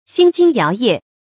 心旌摇曳 xīn jīng yáo yè 成语解释 旌：旗子；摇曳：摆动。